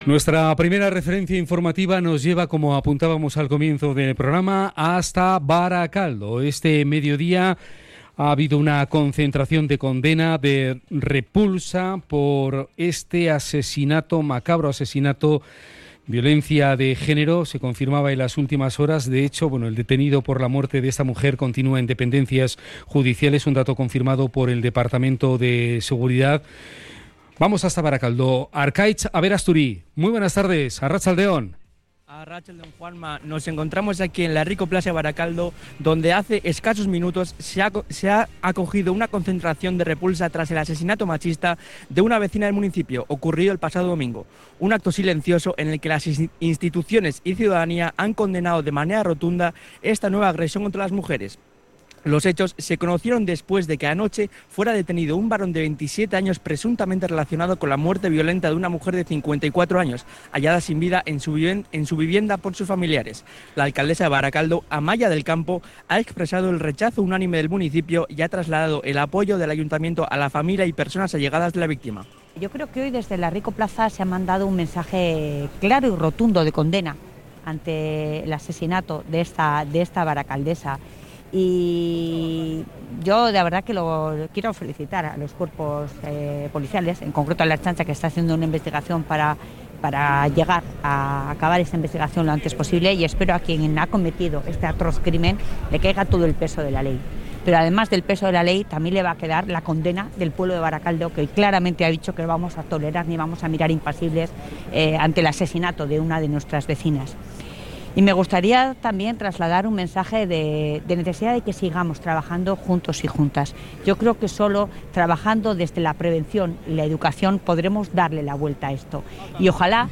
CRONICA-BARAKALDO.mp3